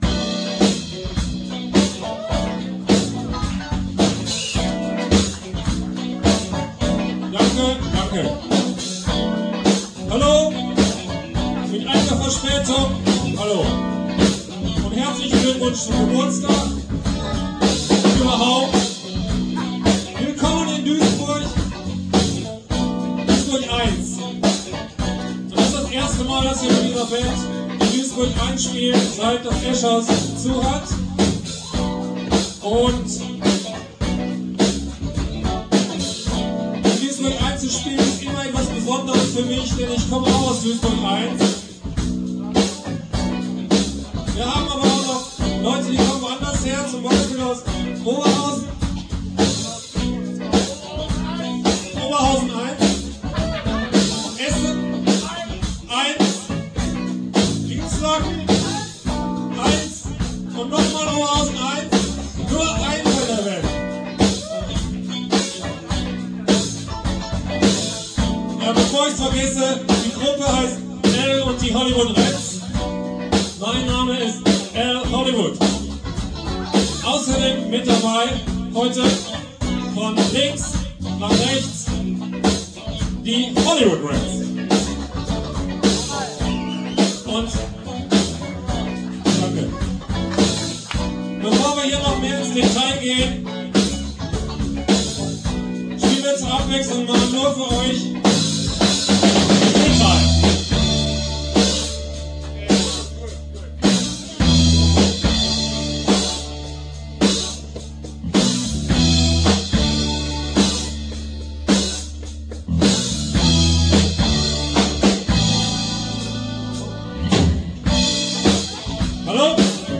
Gesang, Gitarre
Bass
Sologitarre
Schlagzeug